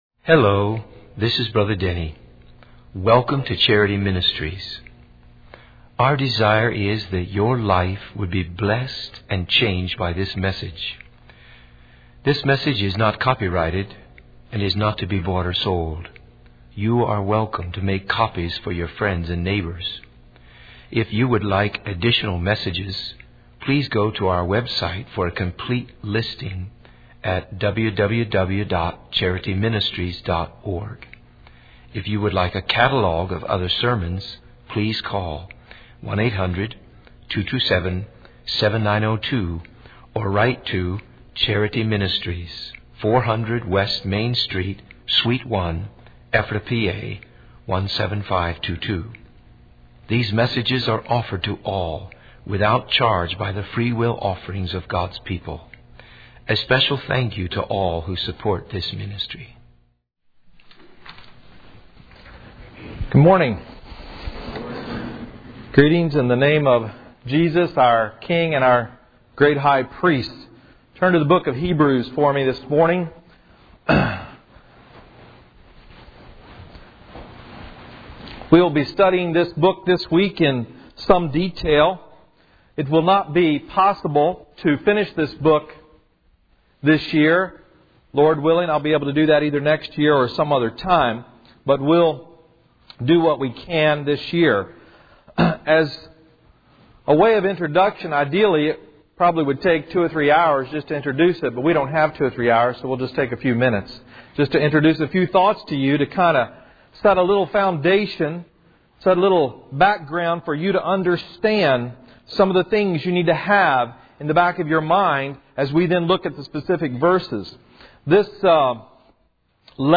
In this sermon, the preacher emphasizes the importance of recognizing the difference between the servants of God, the angels, and His Son. He urges the audience to pay close attention to the message they have heard from God through His Son. The preacher warns against letting the truth of God's word slip by or be disregarded.